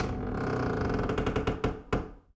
door_A_creak_09.wav